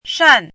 shàn
shan4.mp3